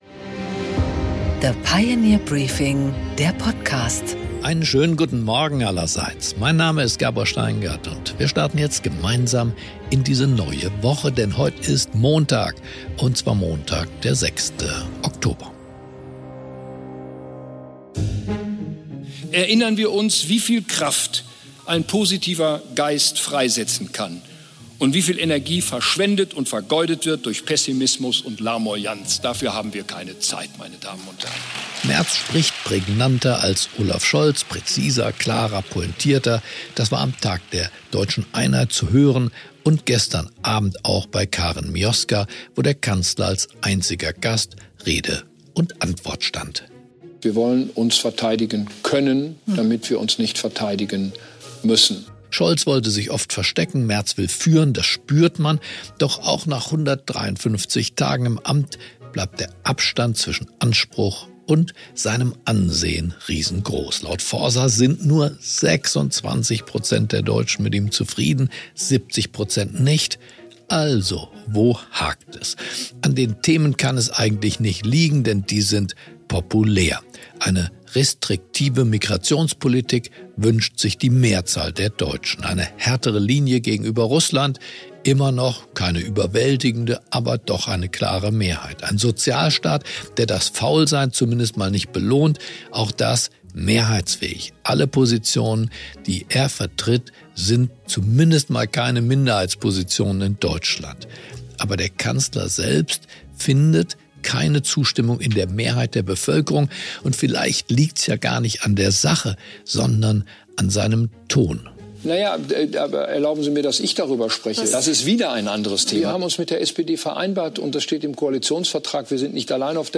Gabor Steingart präsentiert das Pioneer Briefing
Im Gespräch: Prof. Markus Brunnermeier, Ökonomie-Professor in Princeton, fordert im Gespräch mit Gabor Steingart Politik und Wirtschaft auf, ökonomisch und technologisch mehr auf Risiko zu gehen, um Resilienz zu entwickeln. Konkret schlägt Brunnermeier die Kopplung des Renteneintrittsalters an die Lebenserwartung und mehr Flexibilität am Arbeitsmarkt vor.